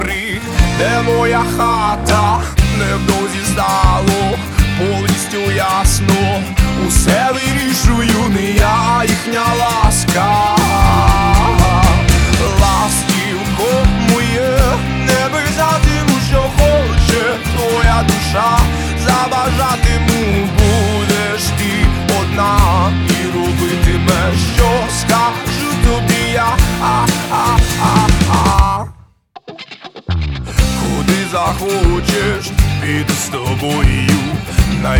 Жанр: Рок / Украинские